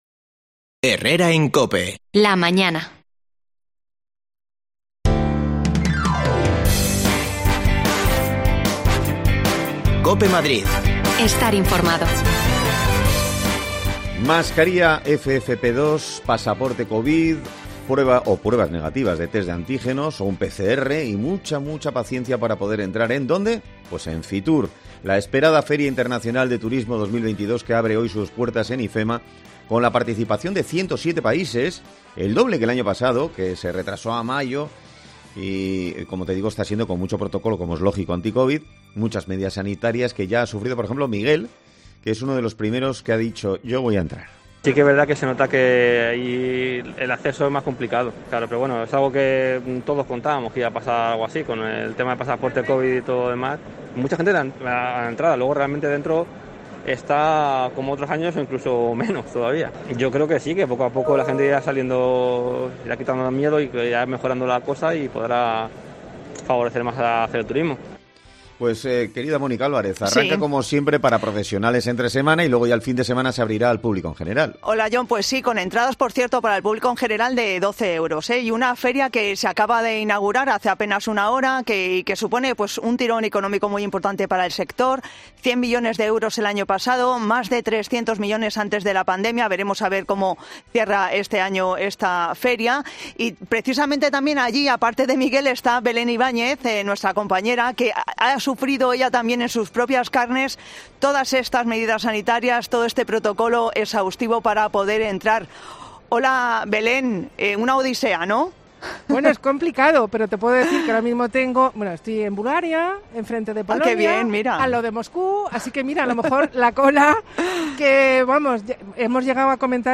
AUDIO: Hoy se inaugura FITUR 2022 con mucho protocolo, con muchas medidas sanitarias. Te lo contamos todo desde allí
Las desconexiones locales de Madrid son espacios de 10 minutos de duración que se emiten en COPE , de lunes a viernes.